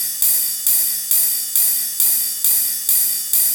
Session 04 - Ride.wav